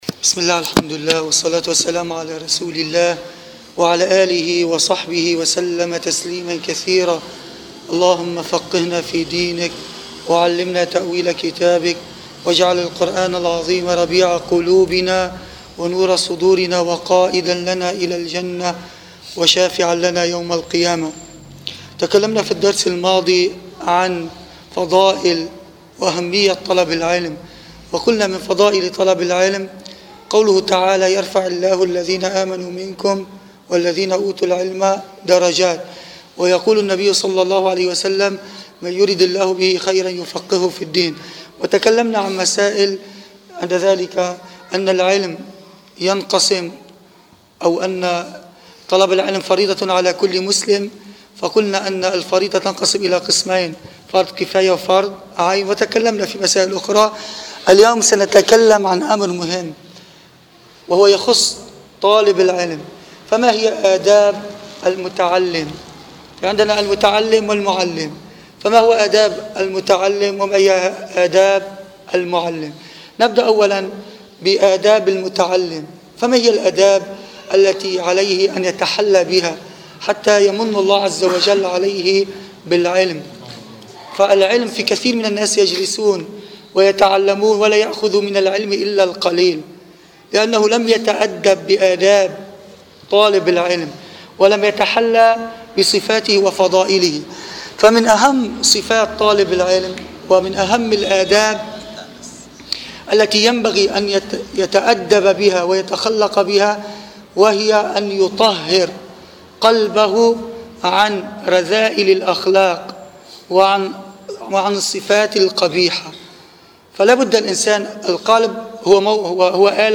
[إيمانيات] شرح “مختصر منهاج القاصدين” – الدرس الثاني
المكان: مسجد القلمون الغربي